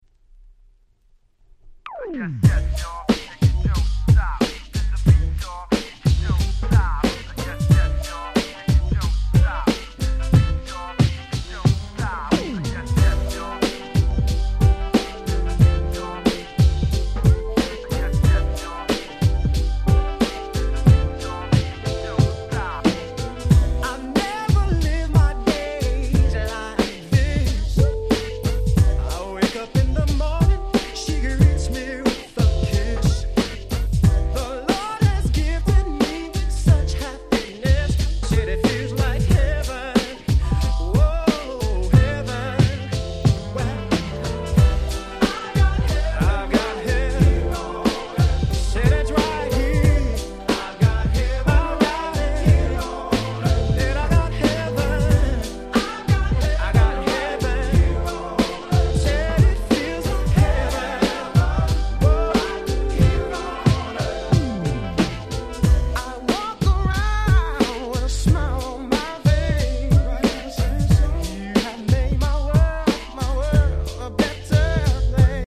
95' Nice R&B !!